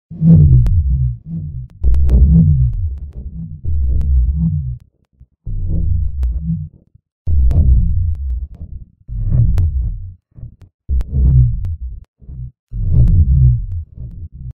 Amber Chorus steuert einen wunderbaren Vintage-Chorus bei, der schön rau klingt.
Das ist aber noch nicht alles: Die Bassdrum soll nach einer Art Maschine in einem entfernten Gewölbe klingen.
Hier Engine 2, immer noch im Solo-Modus: